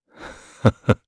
Clause_ice-Vox_Happy2_jp.wav